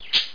bird2.mp3